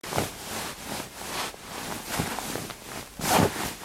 人間　揺する２ ガサガサ